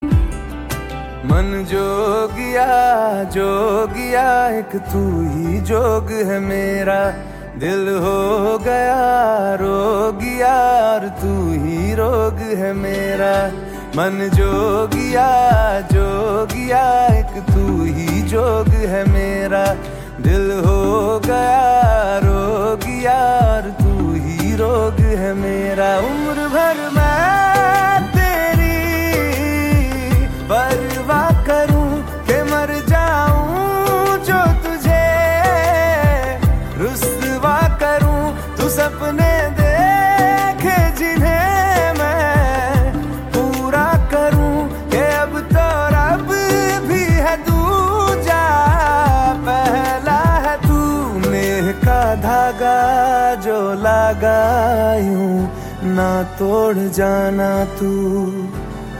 Categories Punjabi Ringtones